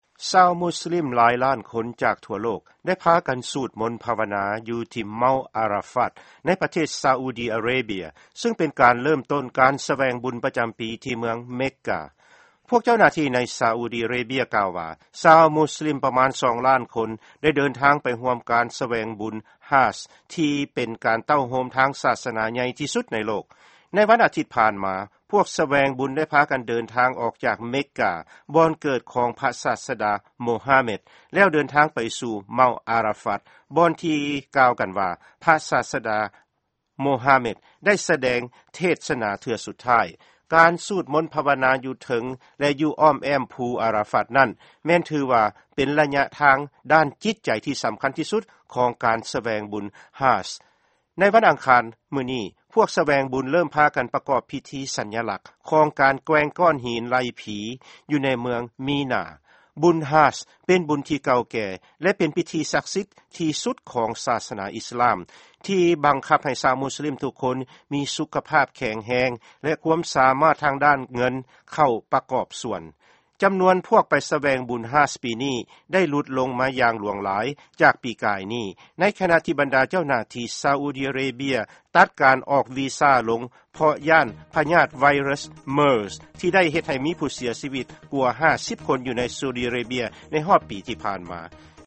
ຟັງຂ່າວ ການສະຫລອງບຸນ Hajj ທີ່ ຊາອຸດີ ອາຣາເບຍ